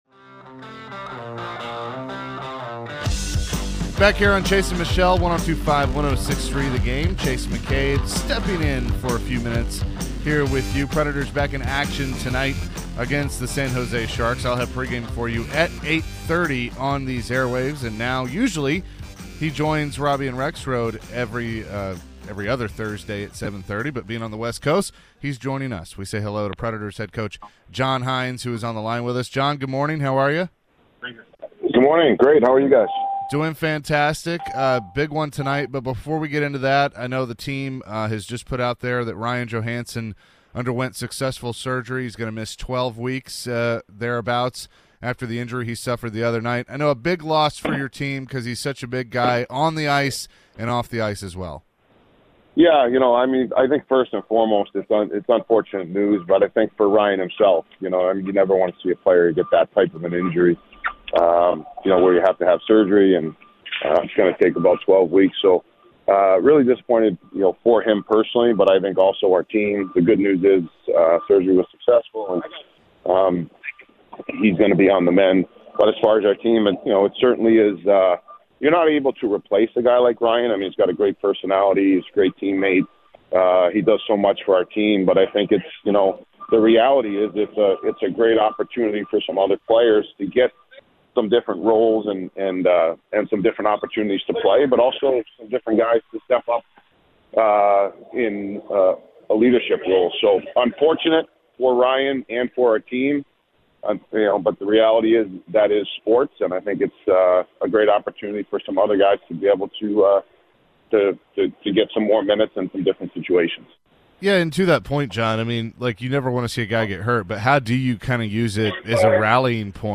John Hynes Interview (2-23-23)